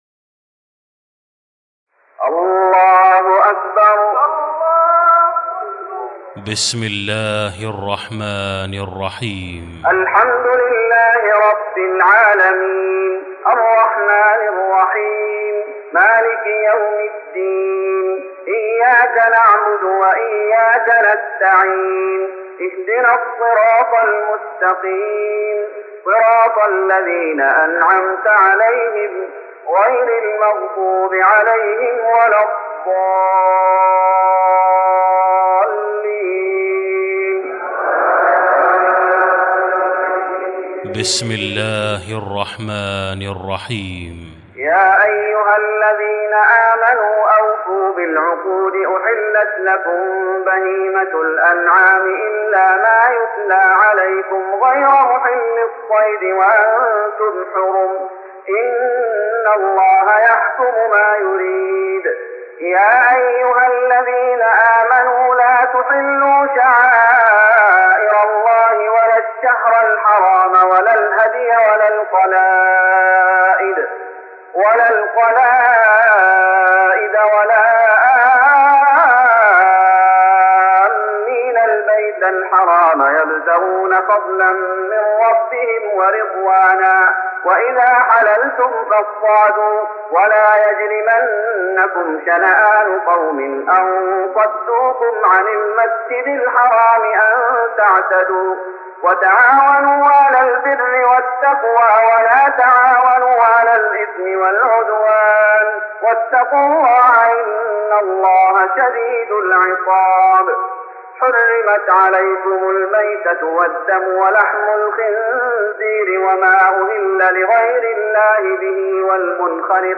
تراويح رمضان 1414هـ من سورة المائدة (1-26) Taraweeh Ramadan 1414H from Surah AlMa'idah > تراويح الشيخ محمد أيوب بالنبوي 1414 🕌 > التراويح - تلاوات الحرمين